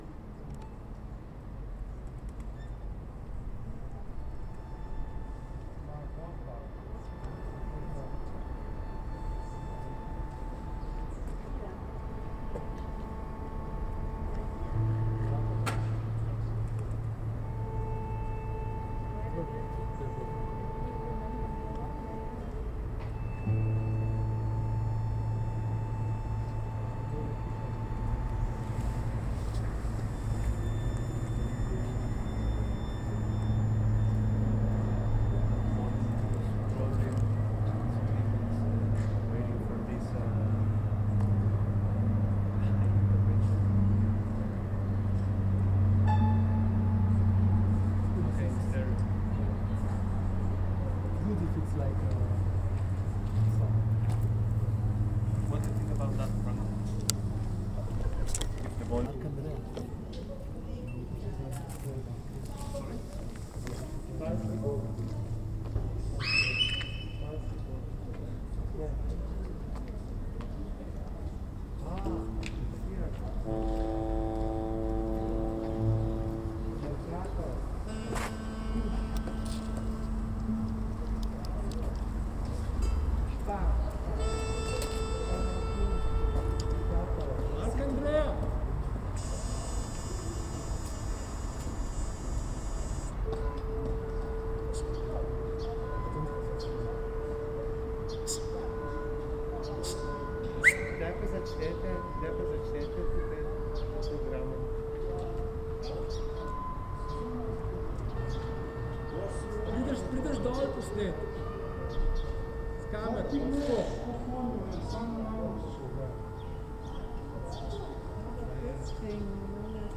on the final afternoon, staging an intervention in the courtyard of the housing complex, with an invitation to residents to join in holding a note, a single tone, together .